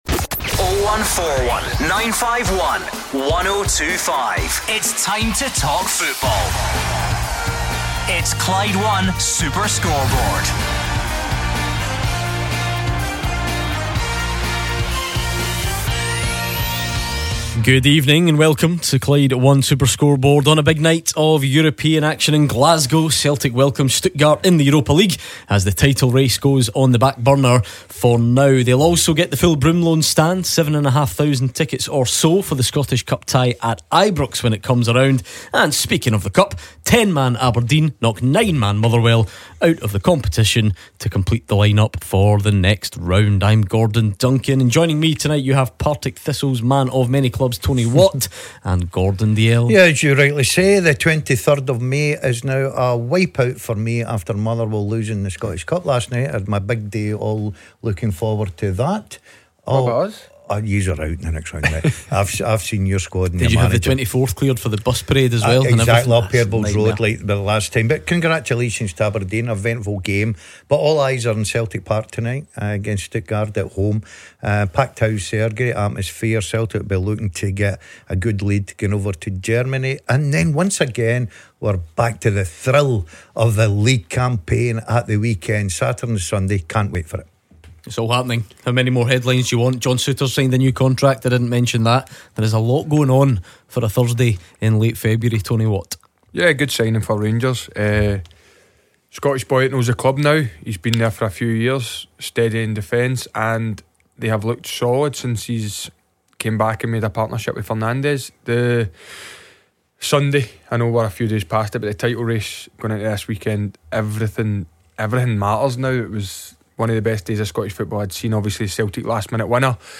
Thursday 17th April | Athletic Bilbao v Rangers Europa League Quarter-final Second Leg LIVE!